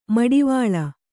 ♪ maḍivāḷa